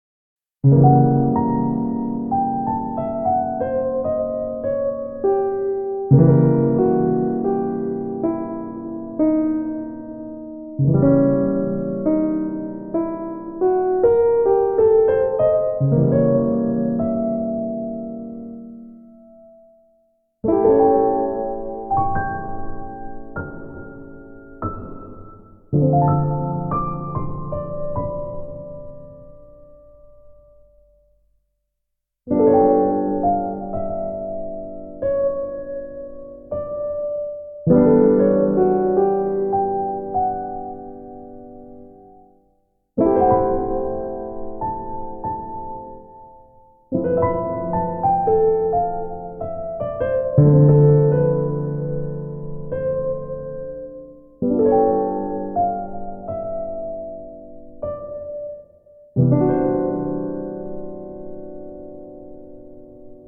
jazz_mastered_0.mp3